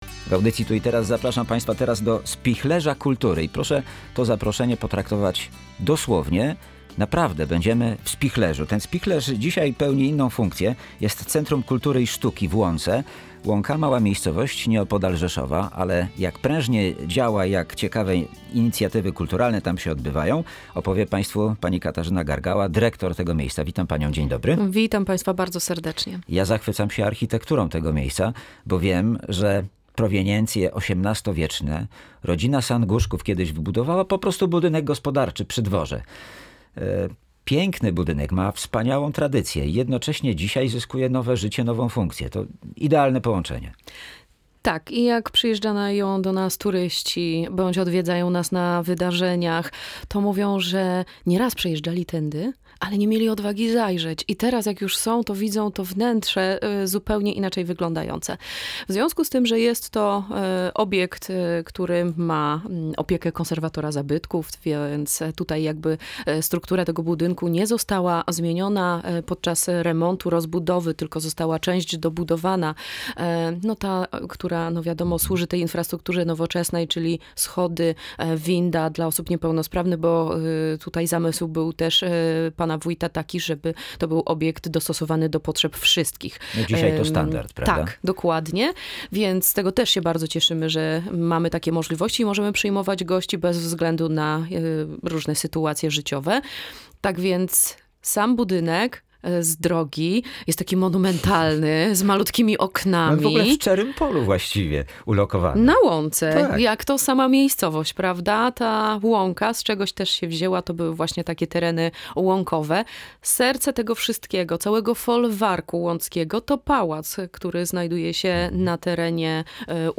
Rozmowę